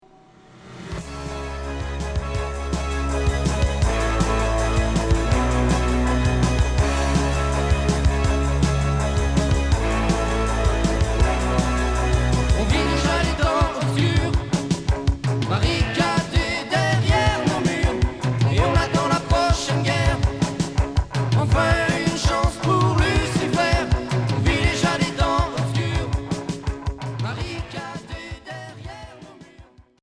Hard progressif Unique 45t retour à l'accueil